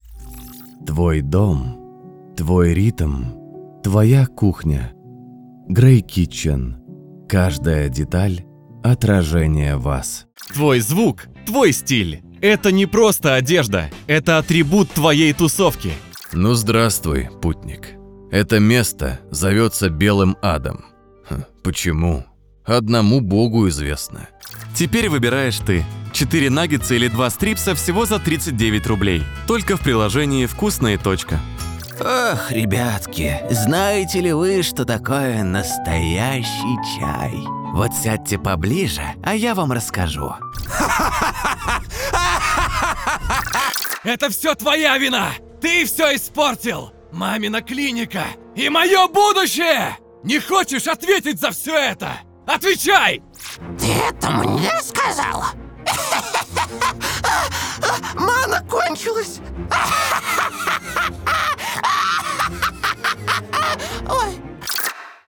Вкуснейший баритон для вашего проекта! Отлично подойдет для Youtube-каналов, IVR, рекламы, рассказов, корпоративных роликов и пр.